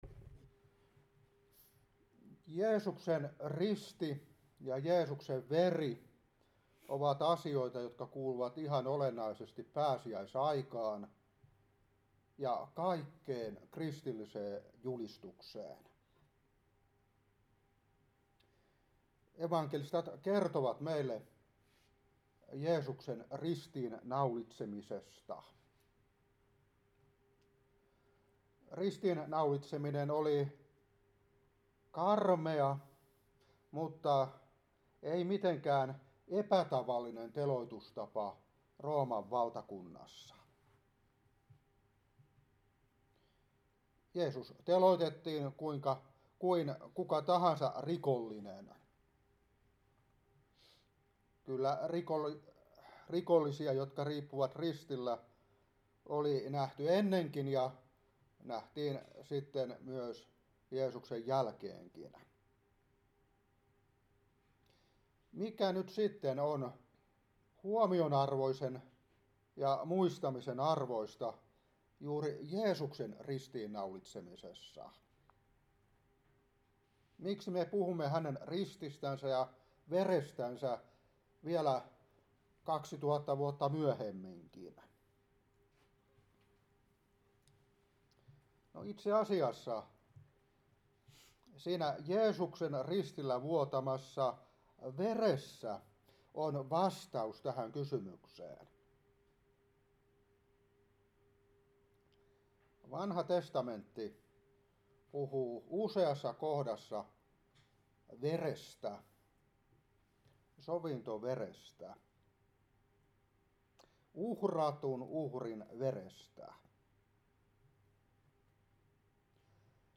Raamattuopetus 2024-3. Hepr.9:6-8,11-12. Kol.1:14. Ef.1:7. 1.Piet.1:18-19. 1.Joh.1:7. Kol.1:19-21. Joh.19:33-35. 1.Joh.5:6-8.